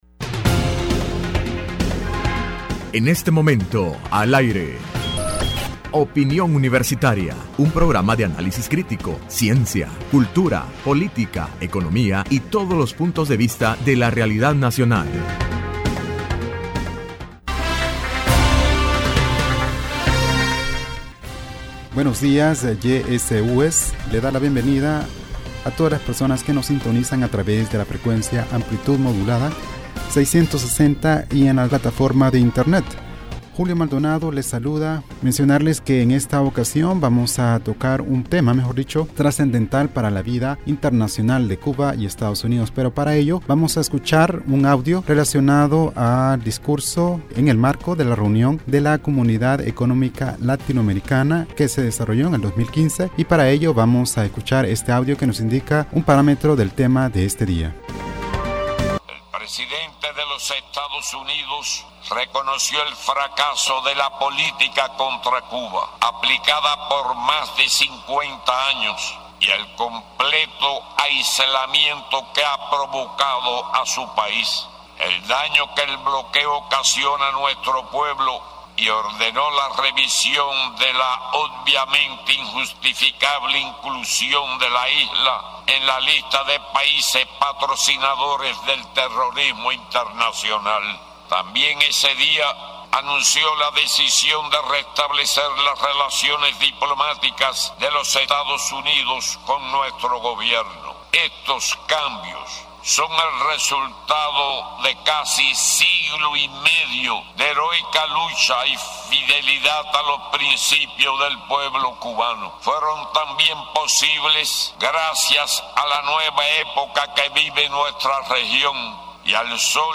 Entrevista Opinión Universitaria(7 Abril de 2016) : Análisis de la Relación diplomática de Cuba y Estados Unidos.